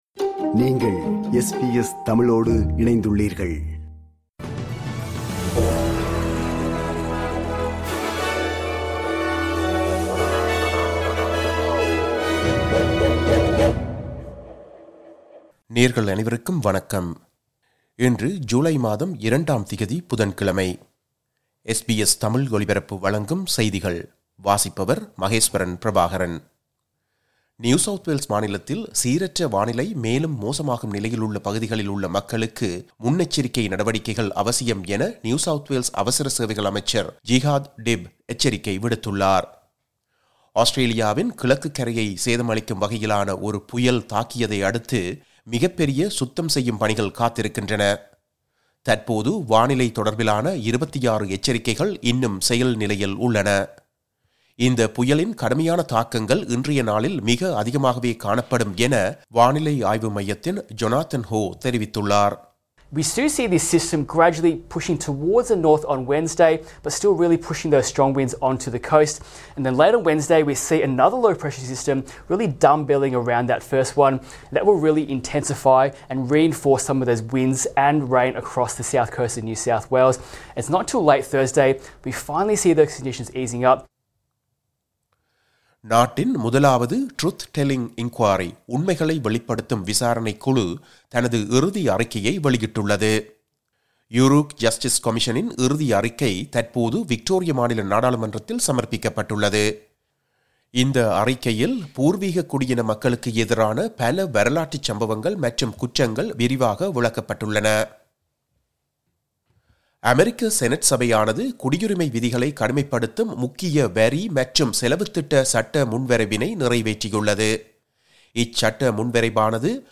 SBS தமிழ் ஒலிபரப்பின் இன்றைய (புதன்கிழமை 02/07/2025) செய்திகள்.